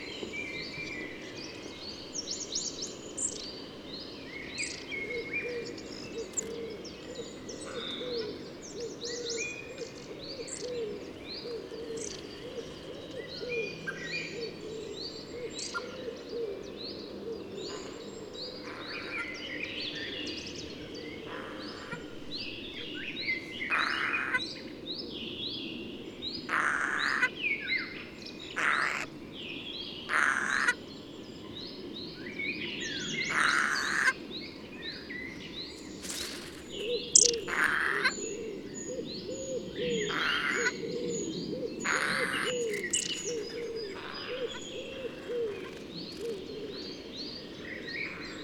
In this recording you can hear two Eurasian Jays Garrulus glandarius who
try to reach the eggs in hidden nest. This brave pair tries to defend it.
100404, Long-tailed Tit Aegithalos caudatus vs. Eurasian Jay Garrulus glandarius,
10_long-tailed-tit_jay.mp3